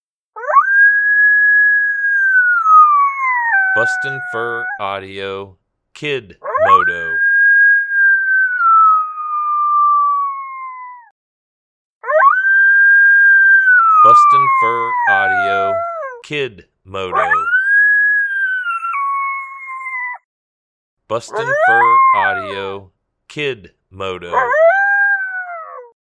Juvenile Male Coyote inviting howl, great stand starter howl.